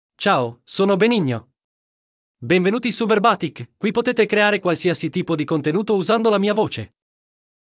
MaleItalian (Italy)
Benigno is a male AI voice for Italian (Italy).
Listen to Benigno's male Italian voice.
Male
Benigno delivers clear pronunciation with authentic Italy Italian intonation, making your content sound professionally produced.